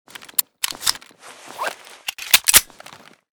pm_reload_empty.ogg